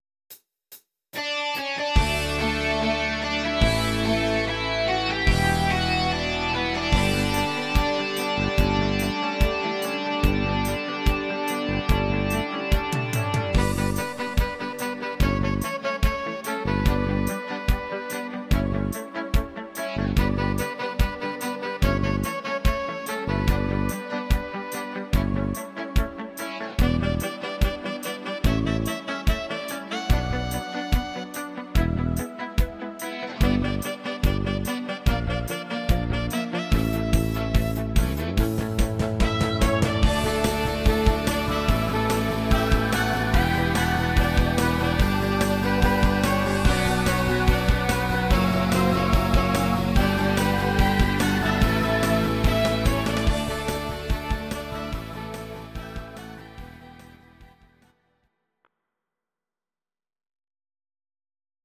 Audio Recordings based on Midi-files
German, 2000s